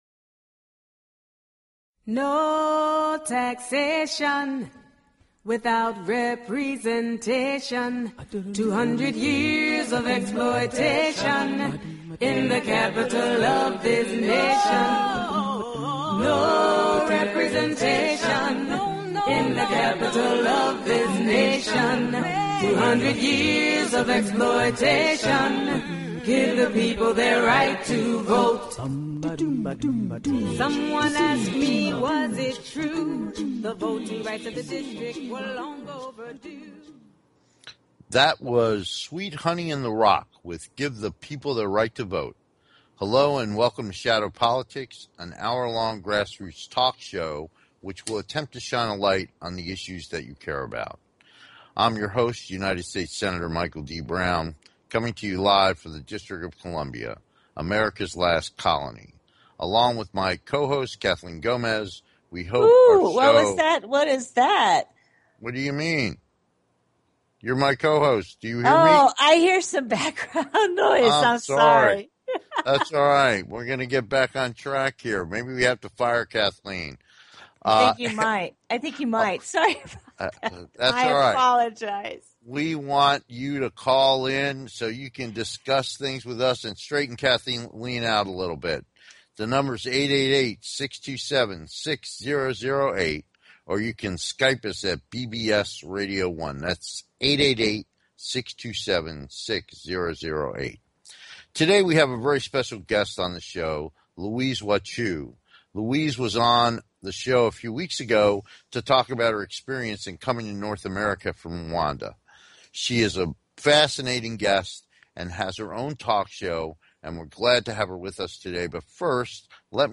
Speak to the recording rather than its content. We look forward to having you be part of the discussion so call in and join the conversation.